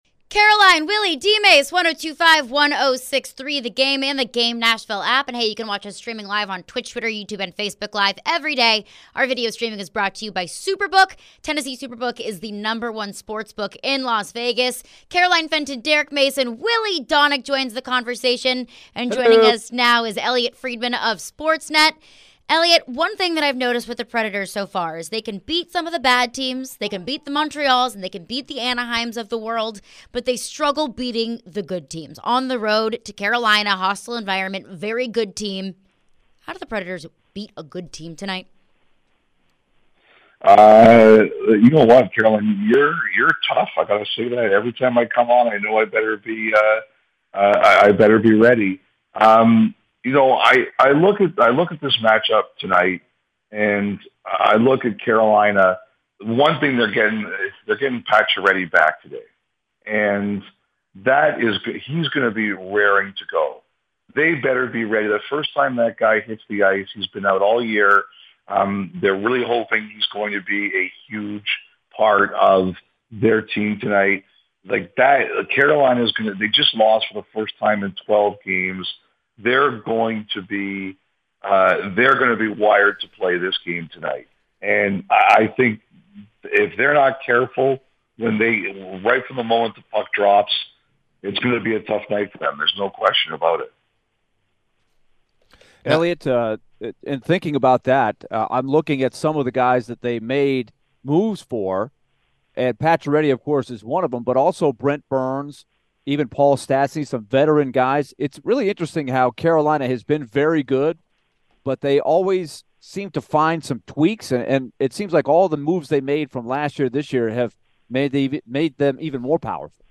Elliotte Friedman interview (1-5-23)